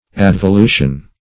Search Result for " advolution" : The Collaborative International Dictionary of English v.0.48: Advolution \Ad`vo*lu"tion\, n. [L. advolvere, advolutum, to roll to.] A rolling toward something.